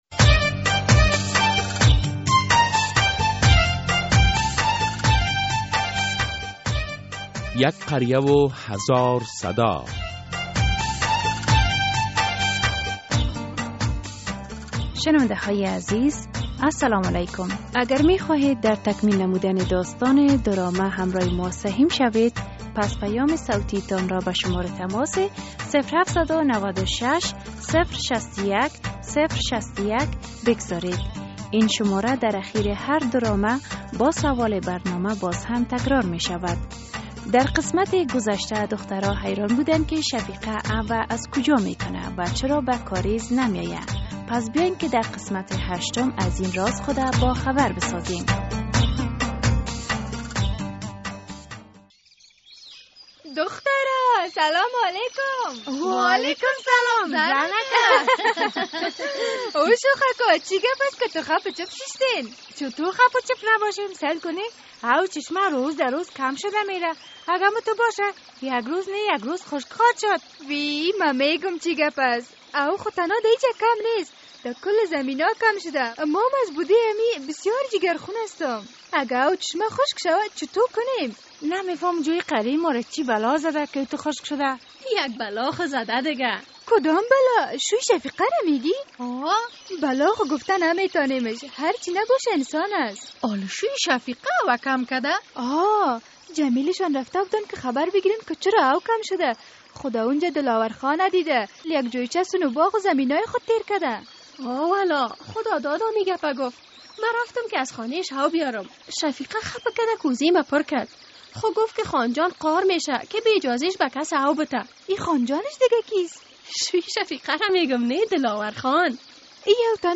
درامهء «یک قریه و هزار صدا» هر هفته به روز های دوشنبه ساعت 05:30 عصر بعد از نشر فشرده خبر ها از رادیو آزادی پخش می شود.